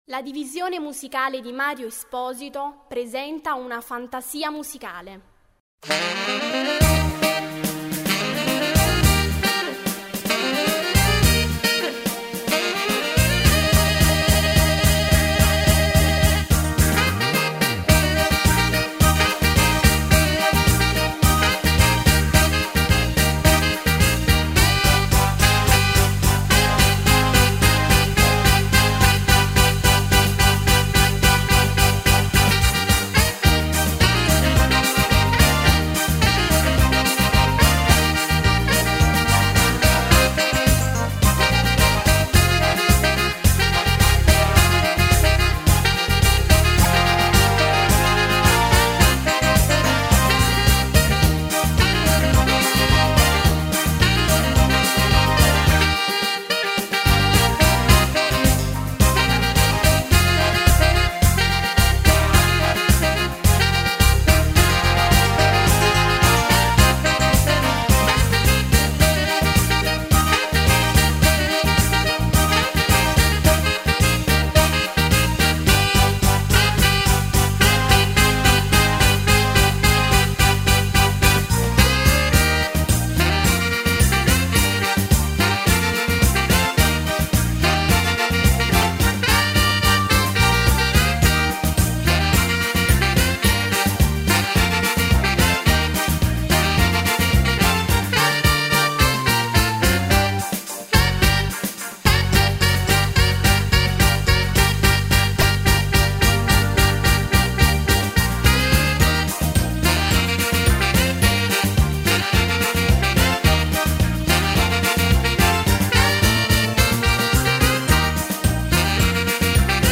Medley